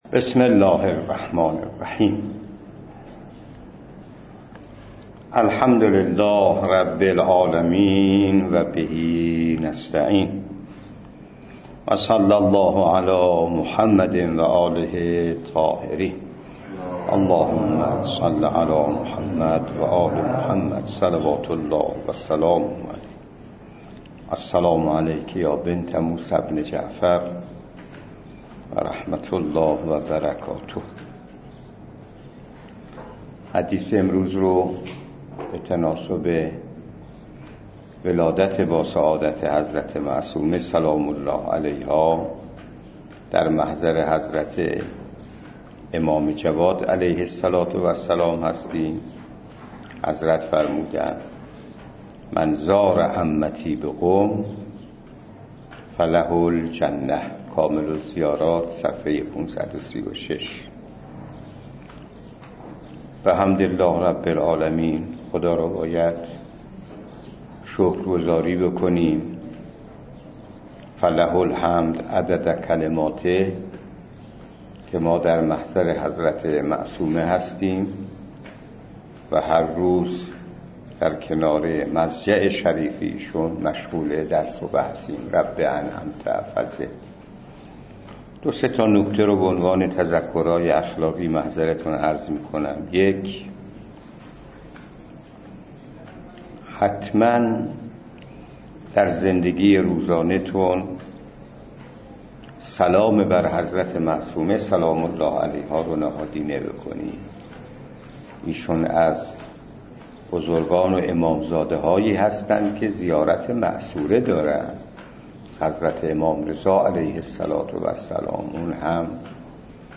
بانکداری اسلامی و معاملات جديد - دروس خارج فقه معاصر